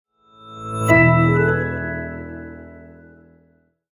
Power Off.mp3